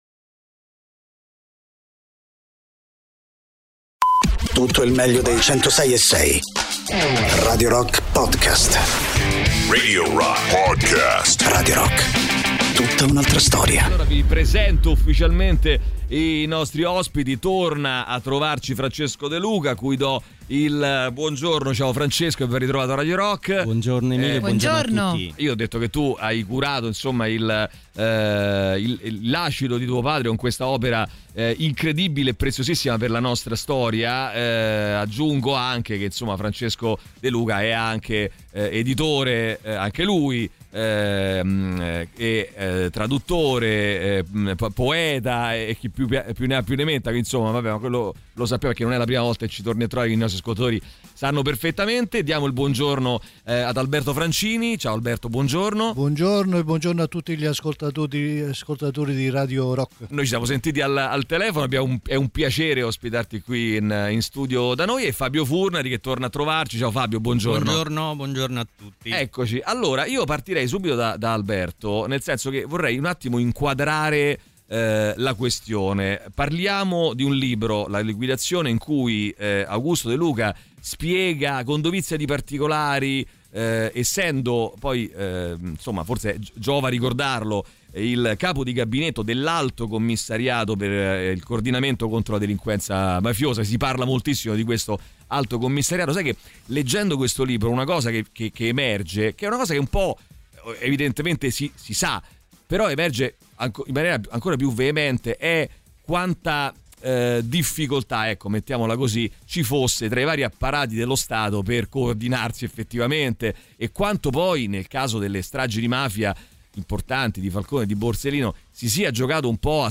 Interviste
ospiti in studio